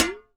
Perc Koopa 2.wav